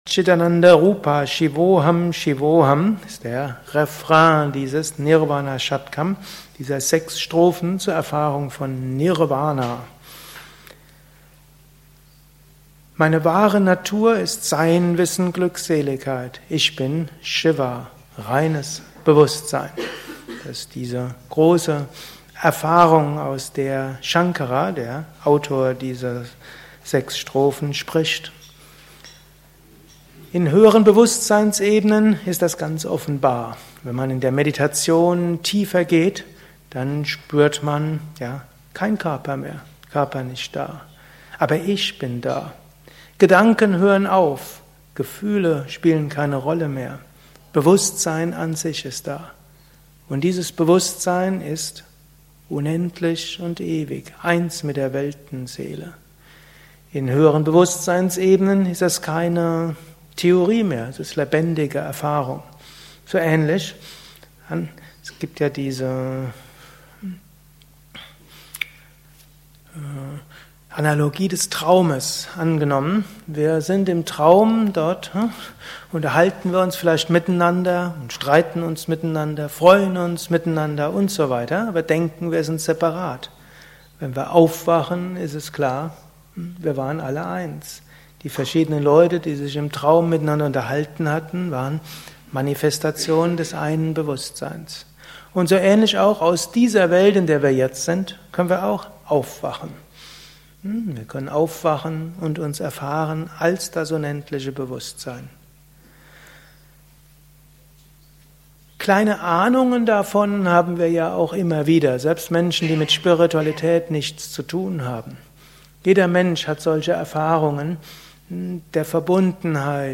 Gelesen im Anschluss nach einer Meditation im Haus Yoga Vidya Bad Meinberg.
Lausche einem Vortrag über: Sechs Strophen über die Befreiung